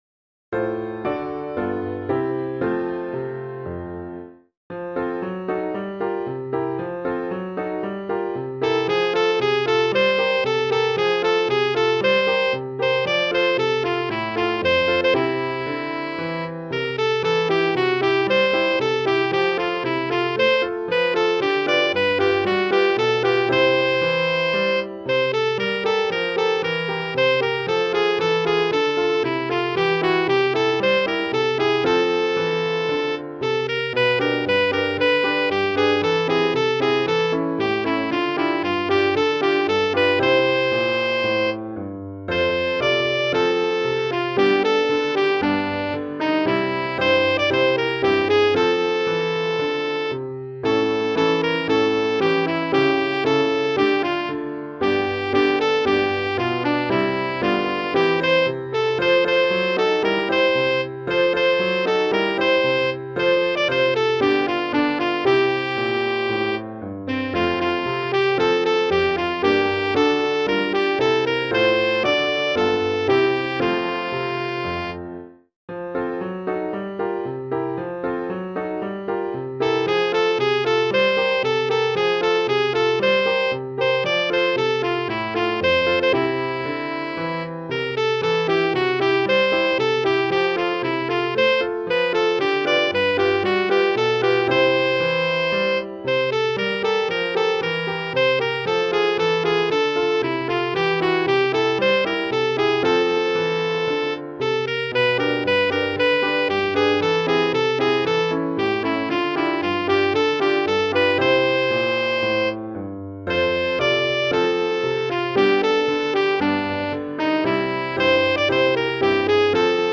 Music Hall Songs: